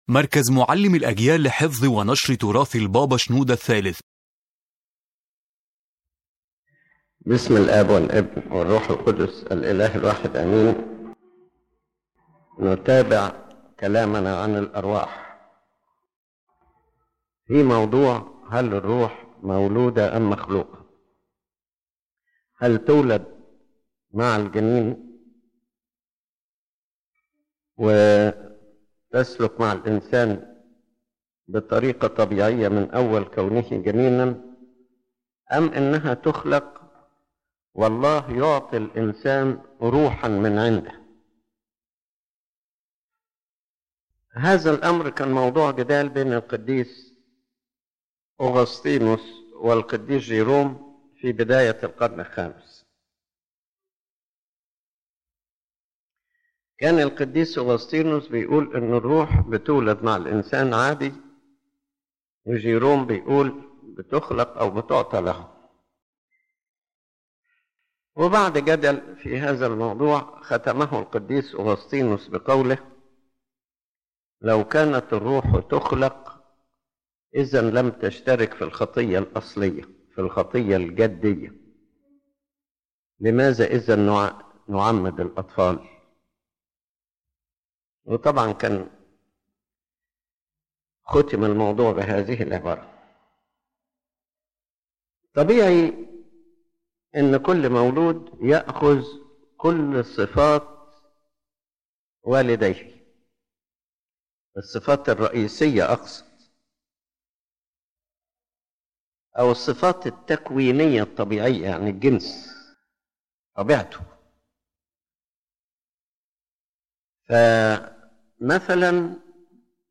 ⬇ تحميل المحاضرة His Holiness Pope Shenouda III addresses an important theological and spiritual issue: Is the human spirit born with the body, or is it created separately for every person?